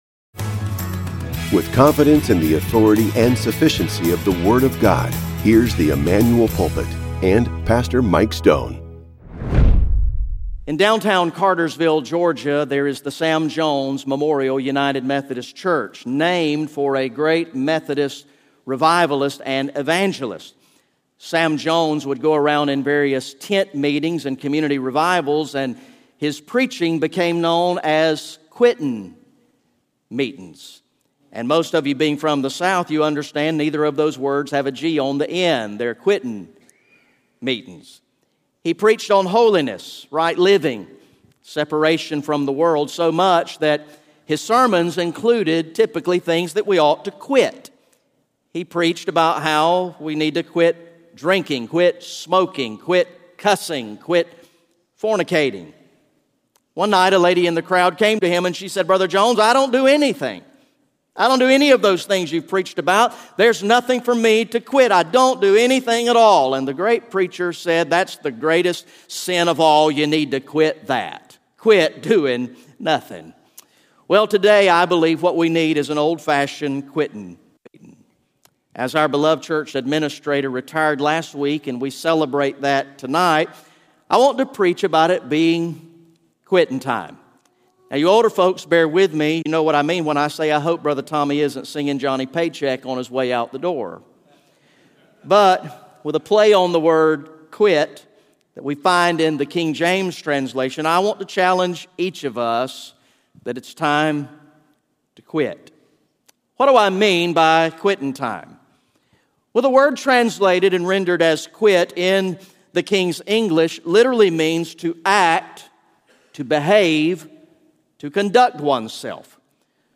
The teaching ministry